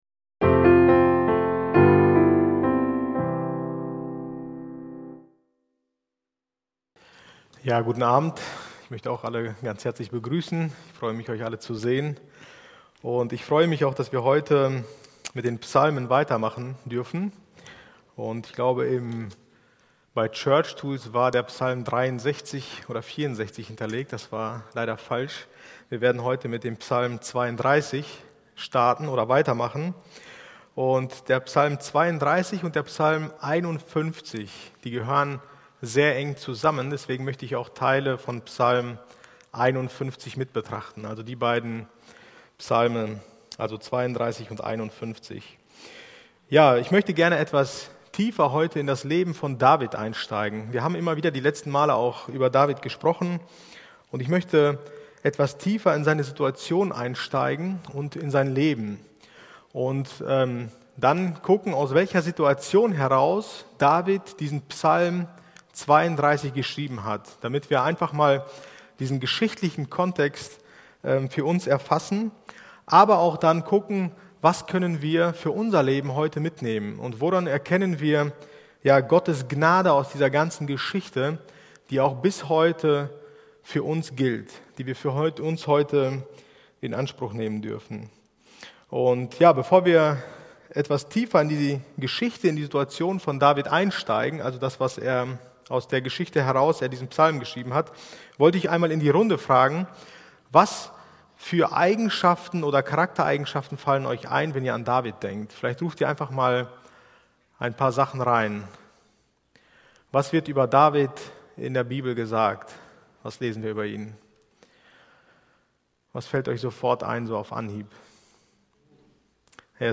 Bibelstunde